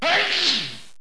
Ahh Choo Sound Effect
ahh-choo.mp3